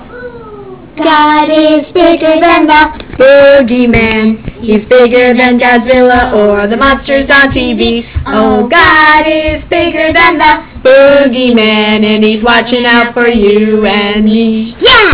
EYES Click for EYES and kids singin